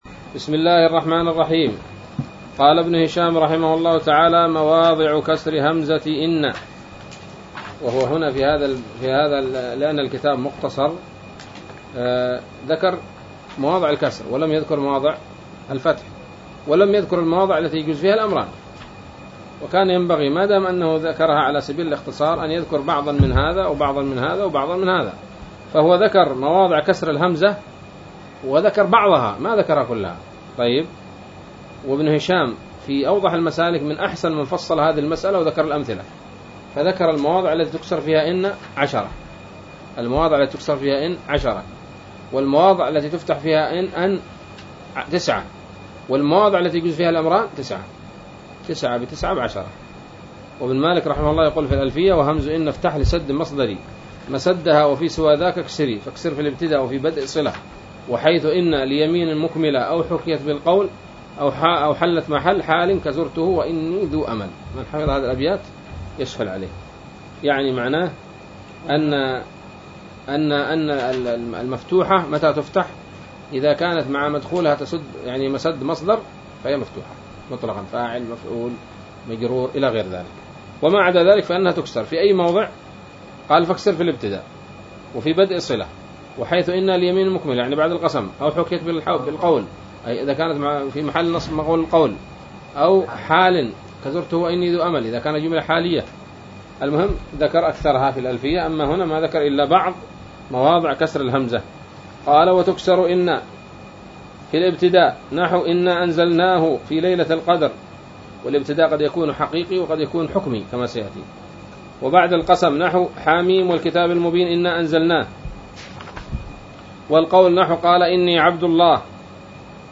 الدرس السادس والستون من شرح قطر الندى وبل الصدى